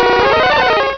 cloyster.wav